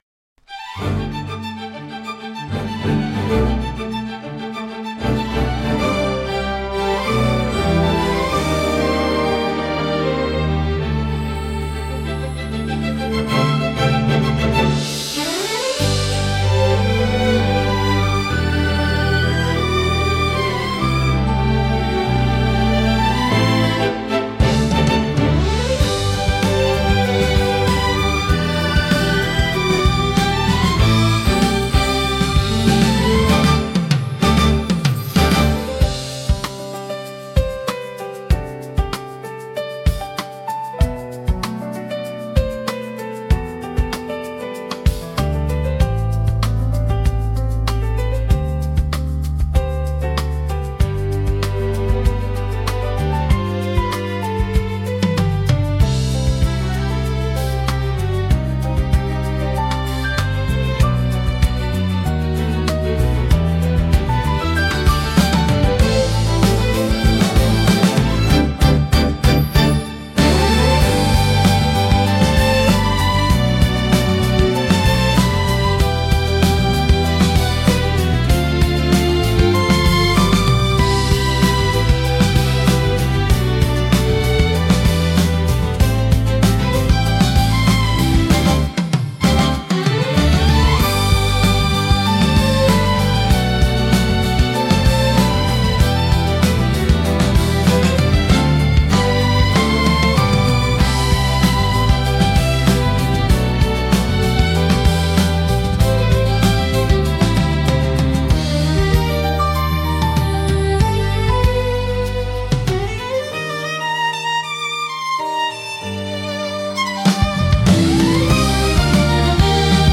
ロマンティックで華やかな場にぴったりのジャンルです。